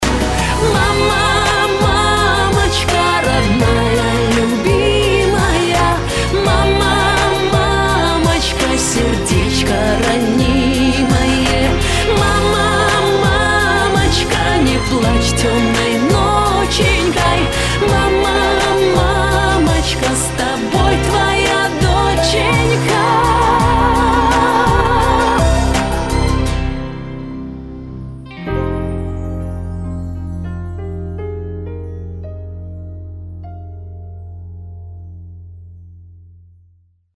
Красивый женский голос